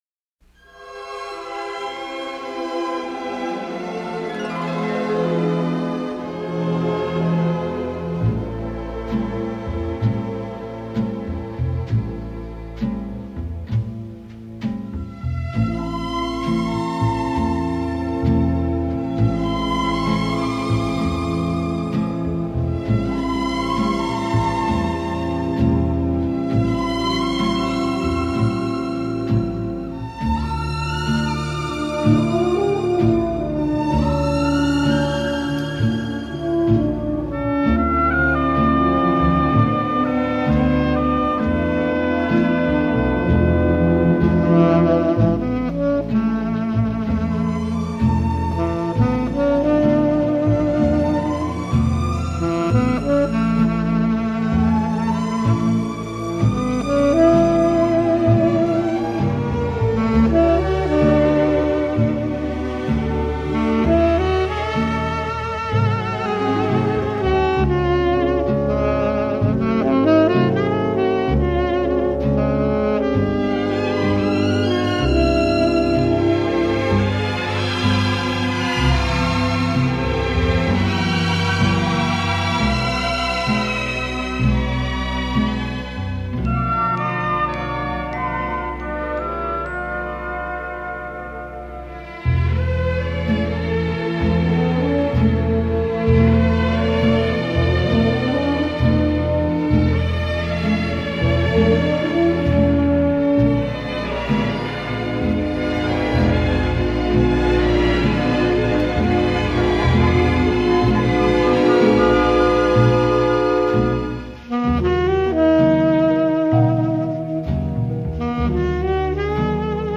Versant mélodique: